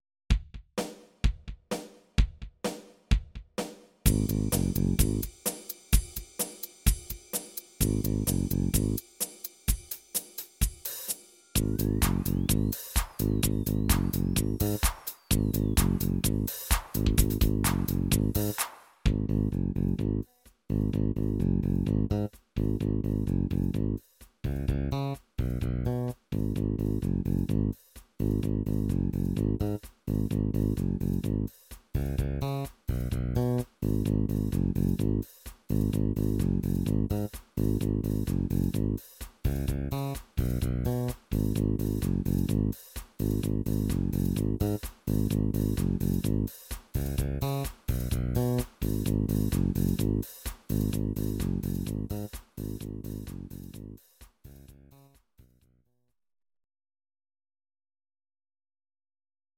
Audio Recordings based on Midi-files
Rock, 2000s